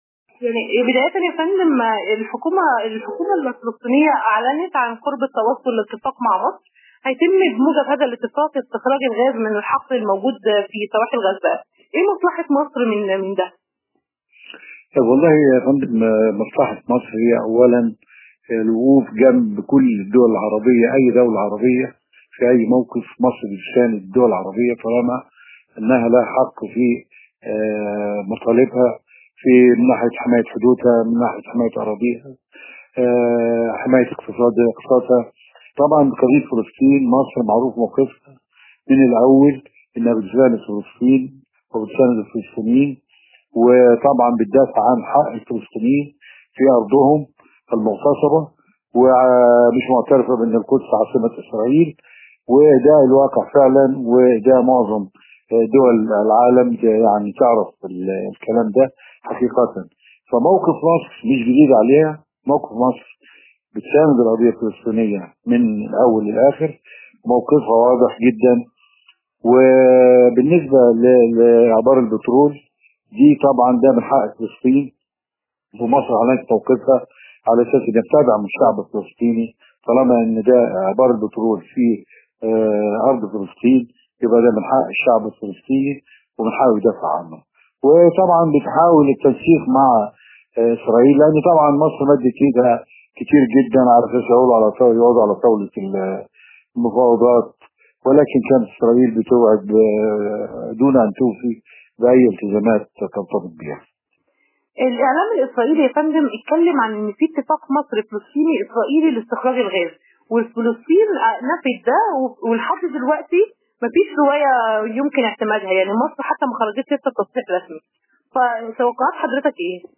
النائب صابر عبد الحكيم، عضو مجلس النواب المصري